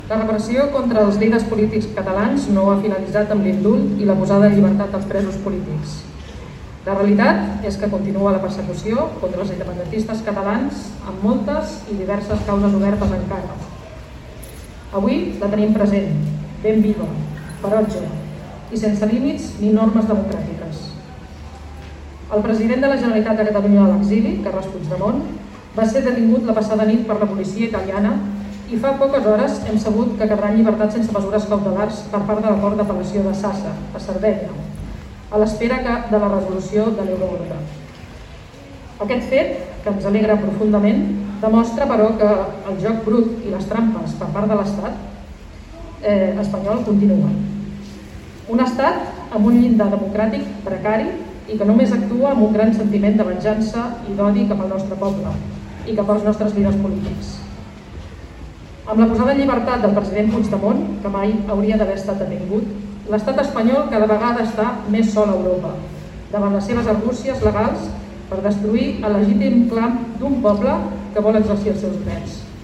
A Tordera, prop d’una trentena de persones es van reunir a la Plaça de l’Ajuntament per aquest motiu. L’ANC Tordera i Òmnium Cultural van ser els encarregats de llegir el manifest.